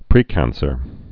(prēkănsər)